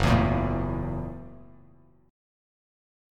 F#7#9 chord